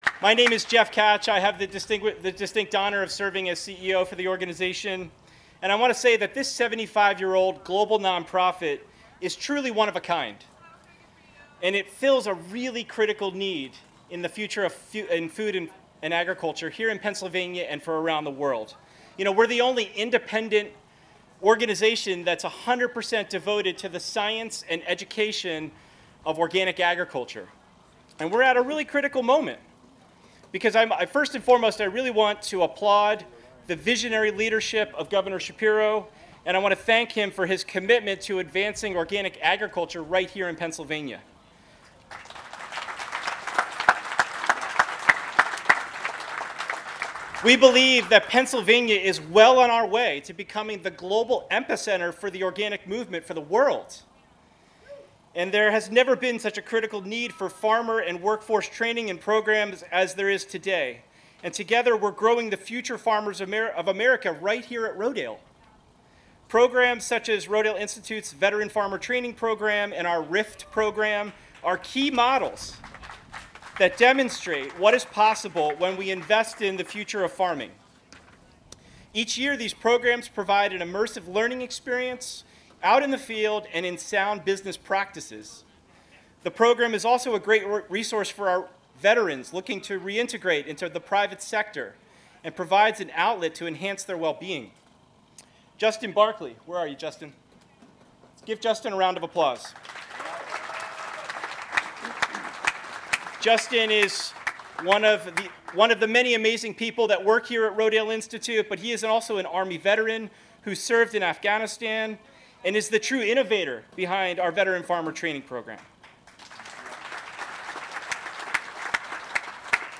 Governor Shapiro and Agriculture Secretary Redding Visit The Rodale Institute in Kutztown, Celebrate Organic Field Day and Meet Veteran Farmers